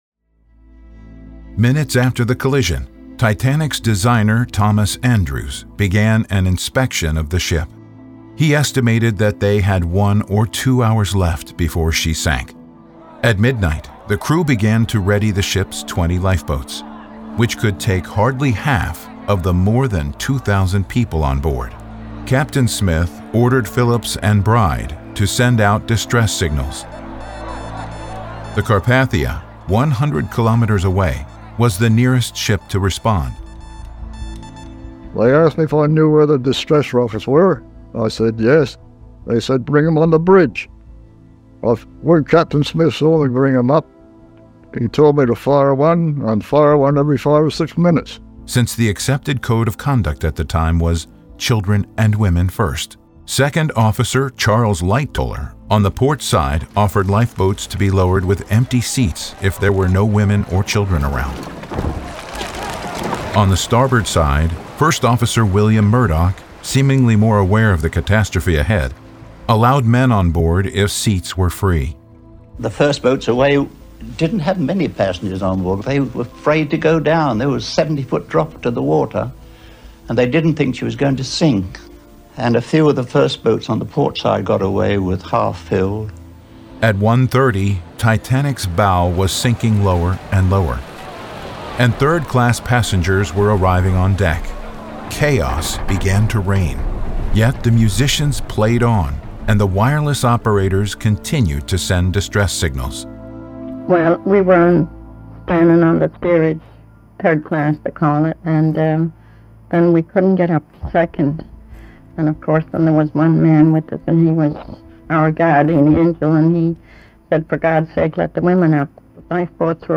• Podcast/Audioguías